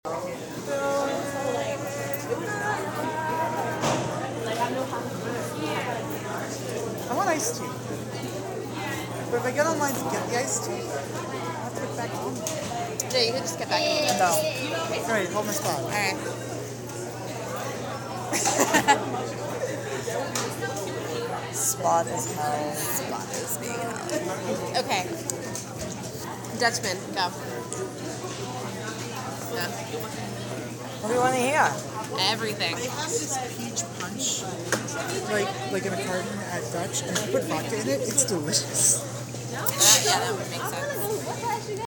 Location: Main floor of Breslin
Sounds: Talking, singing, music, people walking.